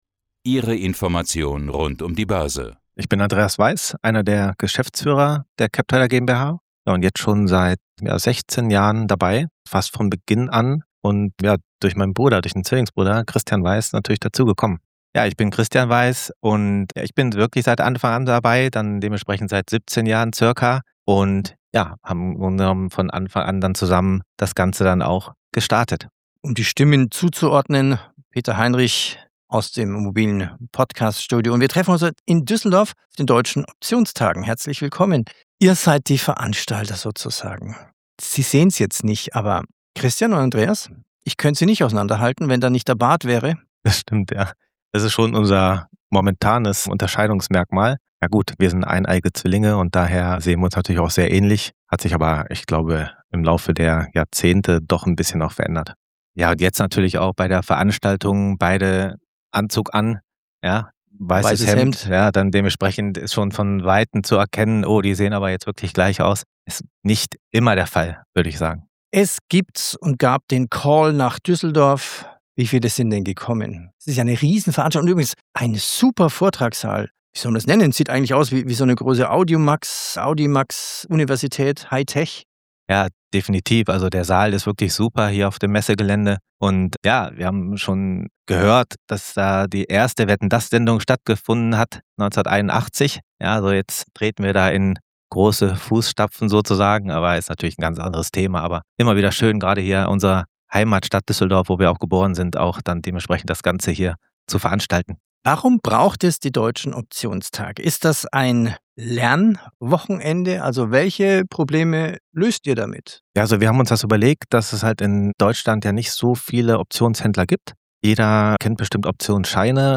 Willkommen zu einem neuen Interview des Börsenradios – heute direkt aus Düsseldorf von den Deutschen Optionstagen 2026. Optionen faszinieren, polarisieren und werden doch oft missverstanden.